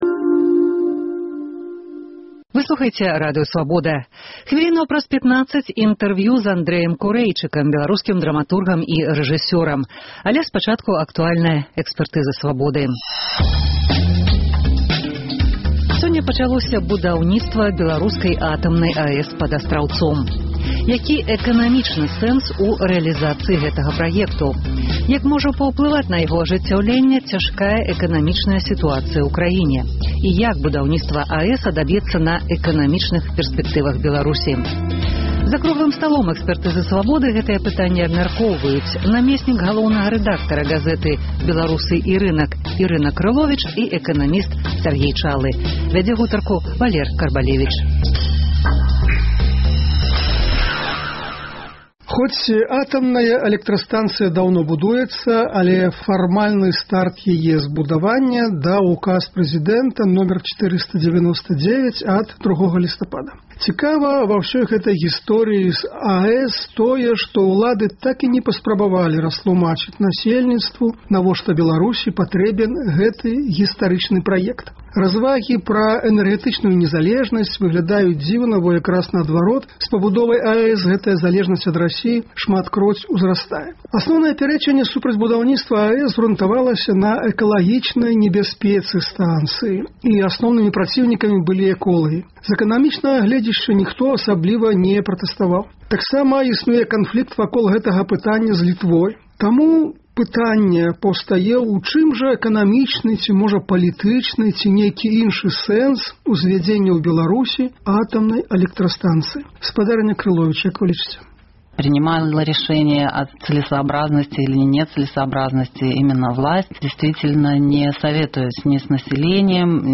абмяркоўваюць за круглым сталом